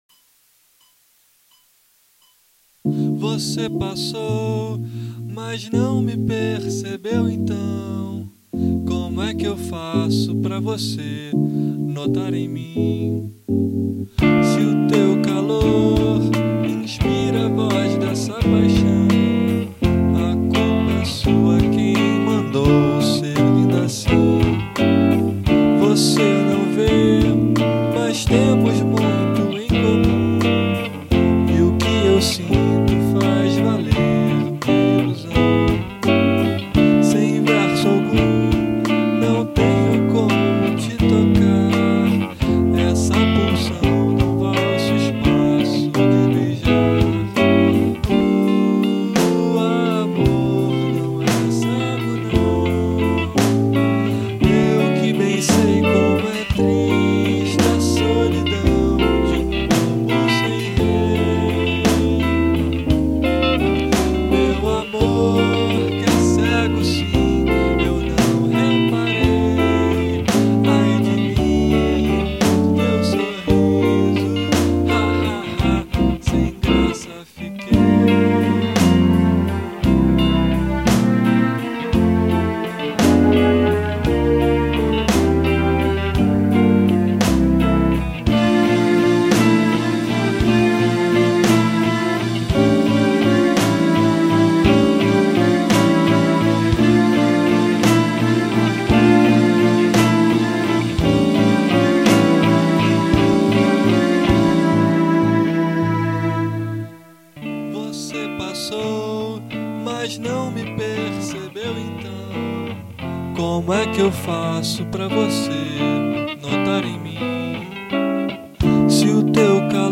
Confira aqui abaixo as duas versões, uma bem basica, que foi material para inscrição no festival, e outra mais produzida.
por A Viuva | Seleção prévia do festival FACHA